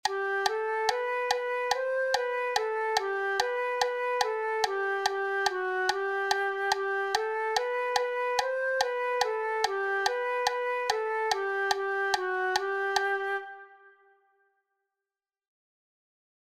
Branle_A_Allegro.mp3